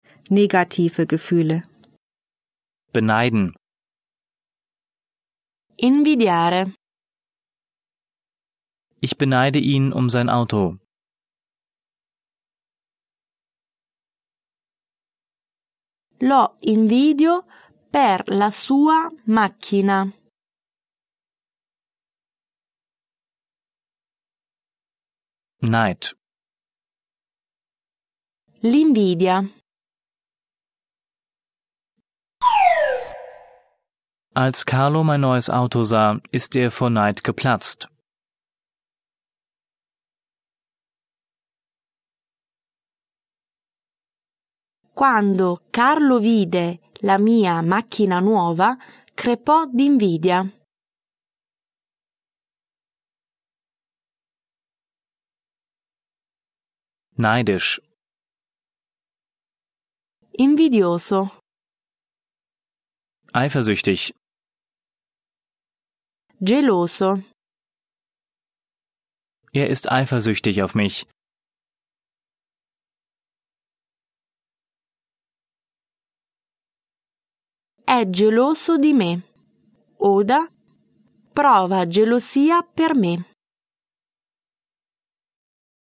von Muttersprachlern gesprochen
mit Übersetzungs- und Nachsprechpausen
Er ist zweisprachig aufgebaut (Deutsch - Italienisch), nach Themen geordnet und von Muttersprachlern gesprochen.